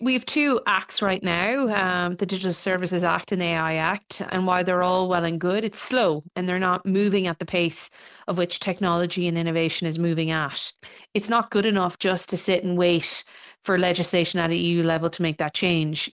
Midlands North West representative Maria Walsh says Europe is taking action, but Ireland can take the lead: